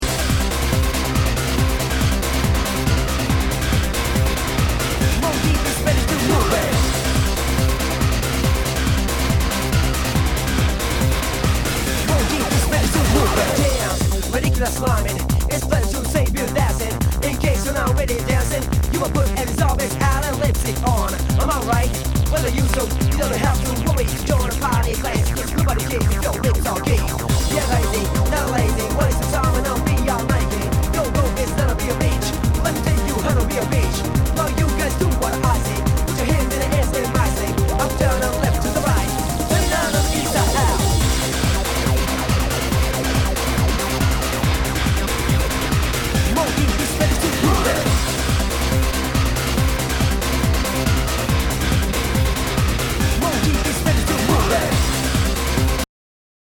HOUSE/TECHNO/ELECTRO
ナイス！ユーロ・テクノ・クラシック！
全体にチリノイズが入ります